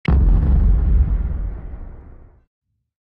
Звуки экшн-камеры: Интро GoPro Hero 7 Black в деталях